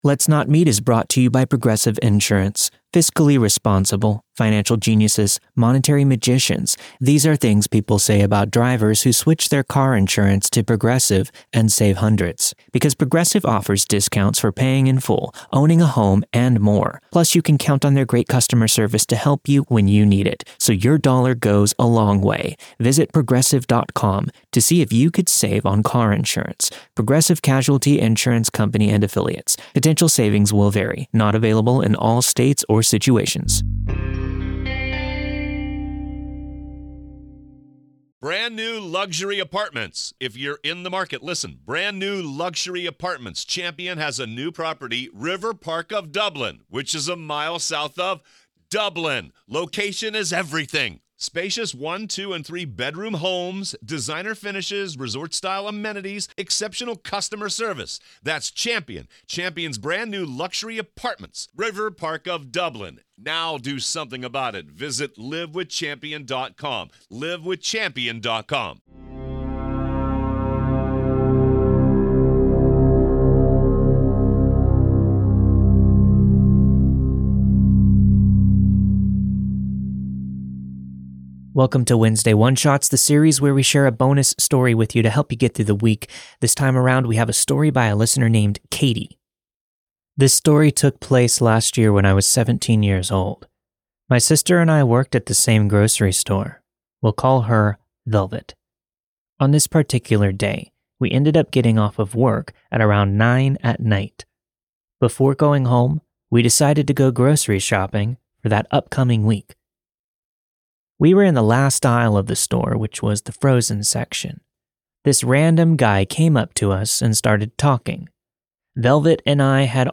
The story you've heard this week was narrated and produced with the permission of its respective author.